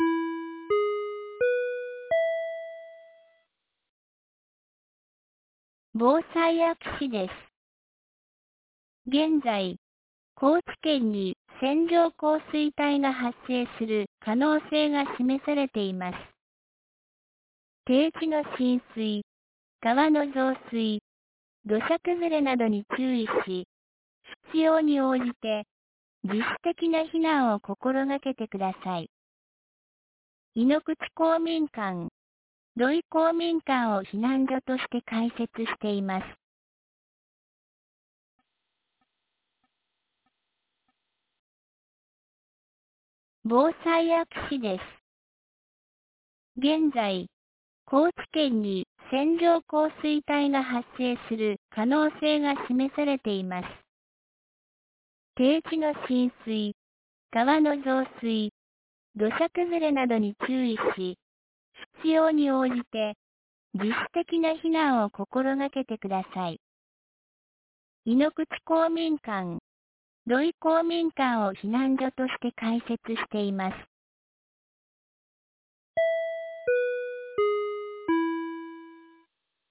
2024年05月28日 09時42分に、安芸市より井ノ口、土居、僧津へ放送がありました。